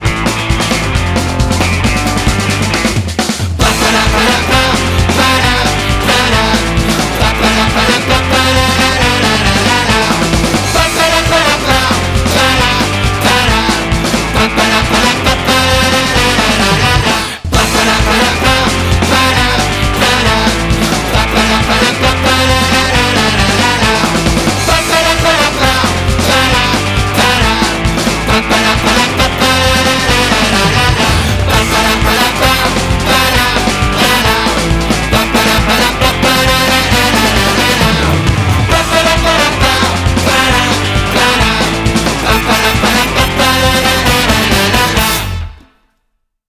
LE JINGLE
Il vous a fait danser, stresser et rire… On se le réécoute ?